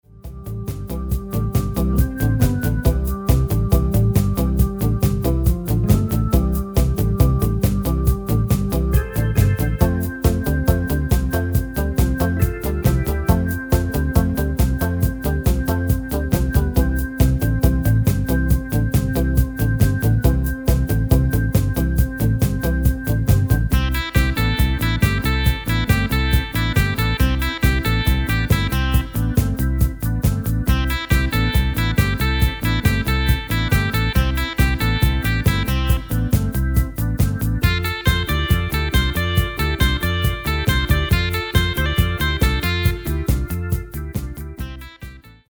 Demo/Koop midifile
Taal uitvoering: Instrumentaal
Genre: Rock & Roll / Boogie / Twist / Rockabilly